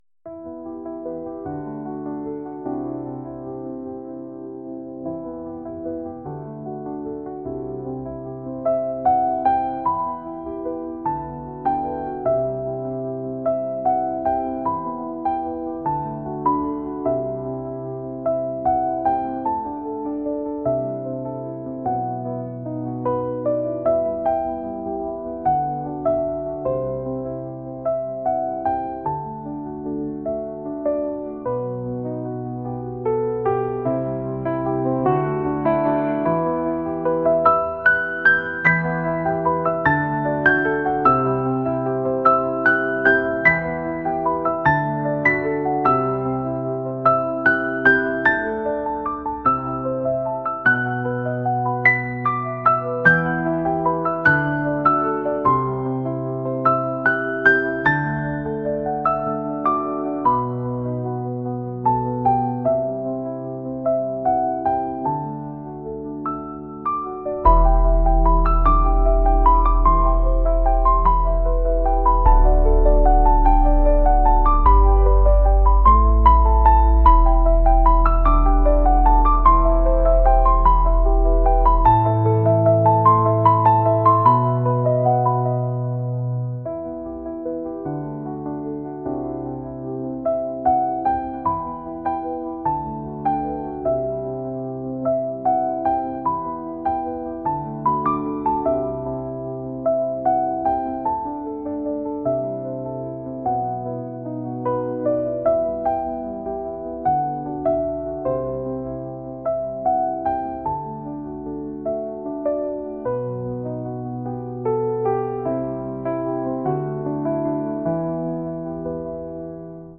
ambient | pop | cinematic